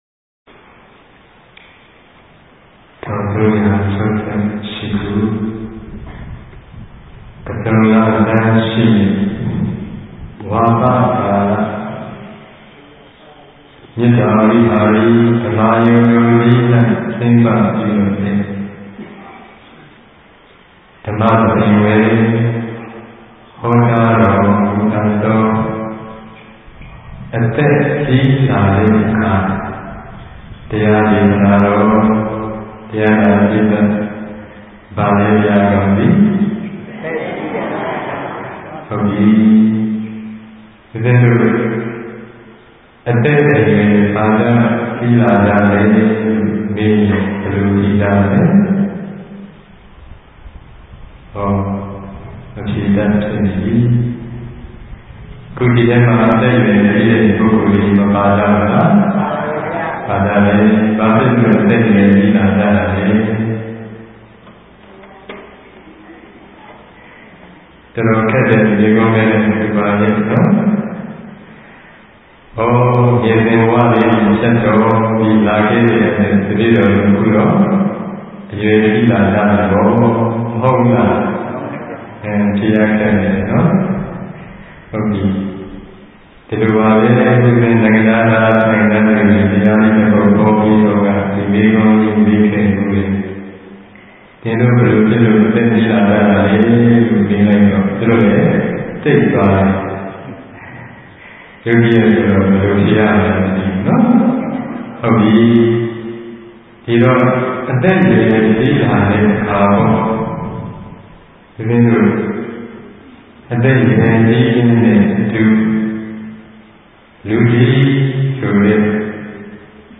အခွေ(၁) ၀ိပဿနာတရားစခန်းများတွင် ဟောကြားခဲ့သော တရားတော်များ